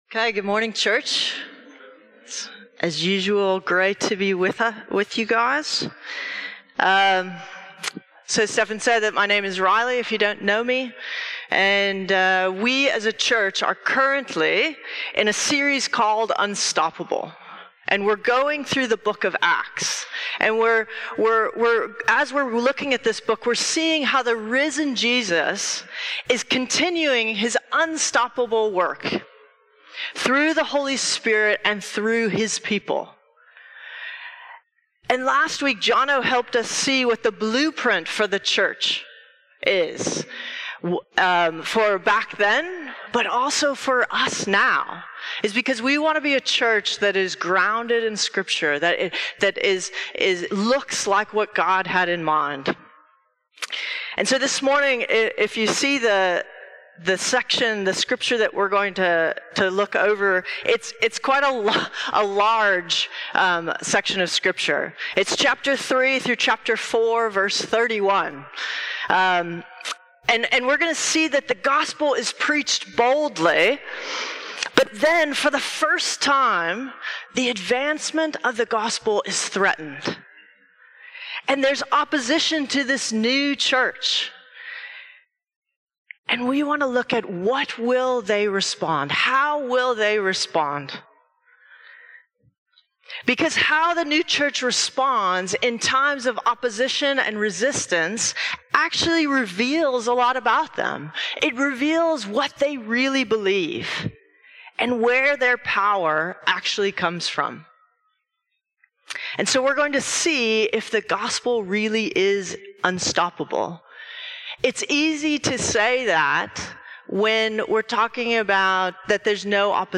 A message from the series "Unstoppable."